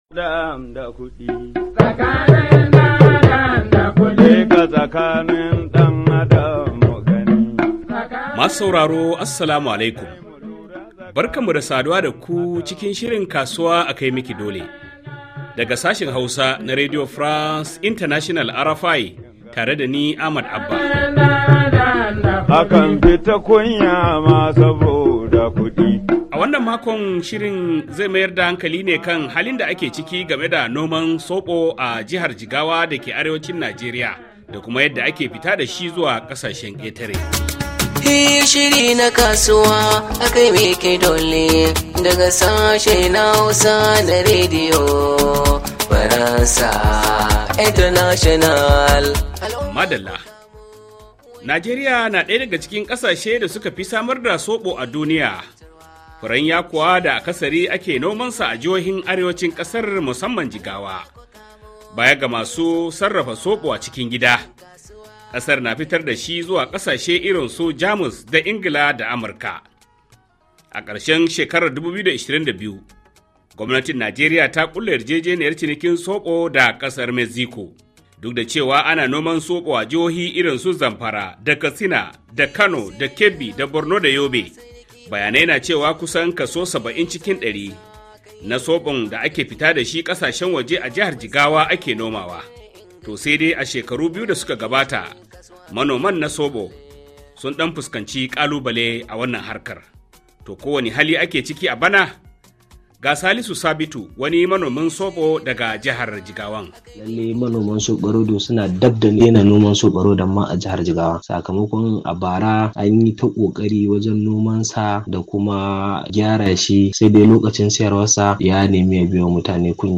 Tare da jin ta bakin 'Yan kasuwa da Masana’antu dangane da halin da suke ciki.